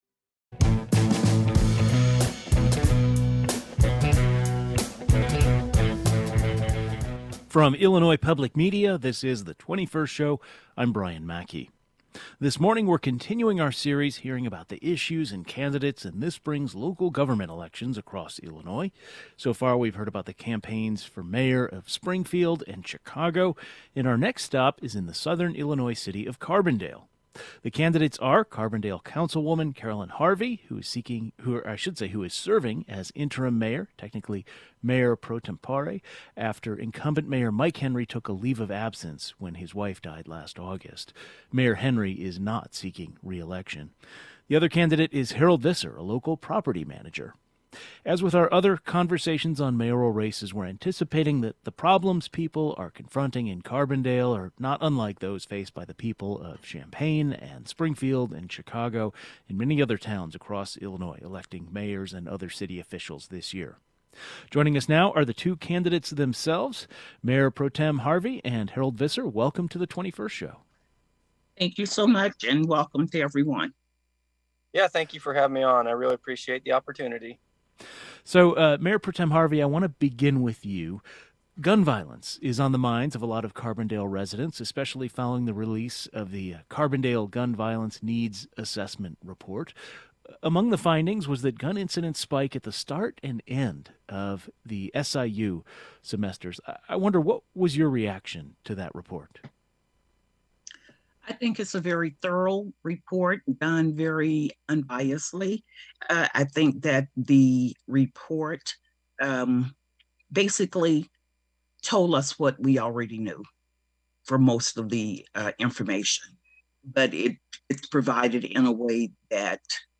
A local business owner and the current mayor pro tempore are both vying for the seat, and both candidates joined us to discuss their policy platforms.